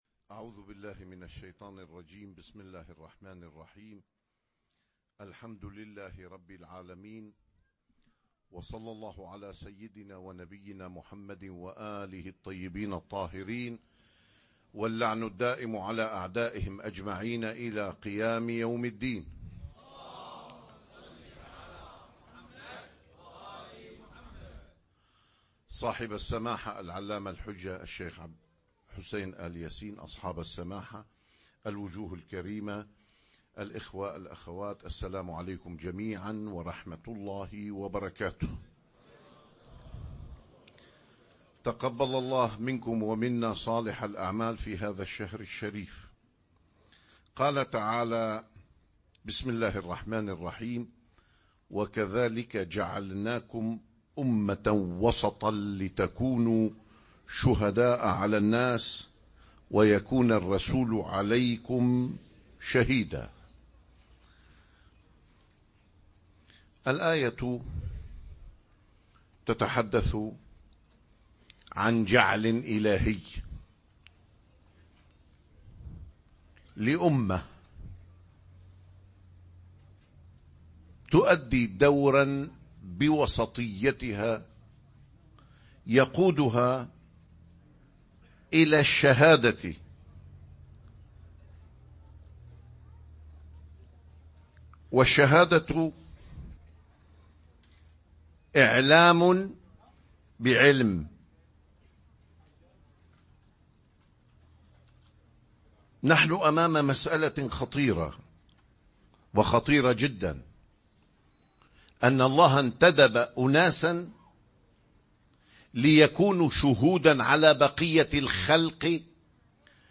محاضرات